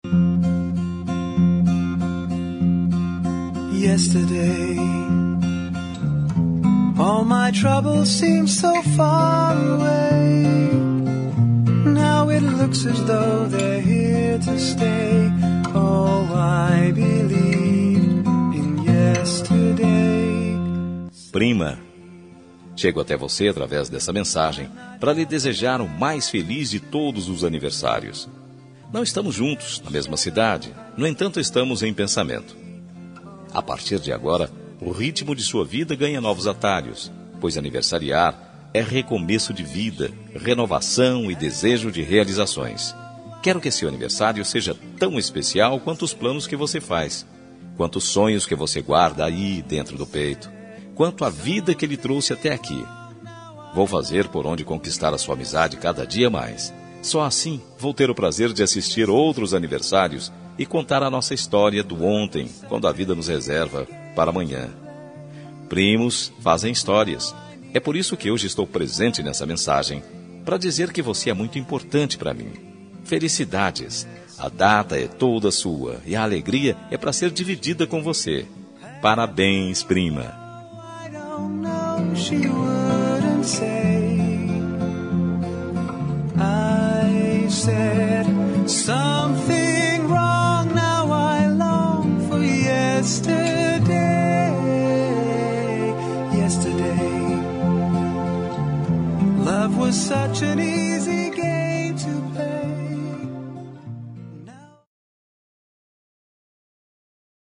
Aniversário de Prima – Voz Masculina – Cód: 042820 – Distante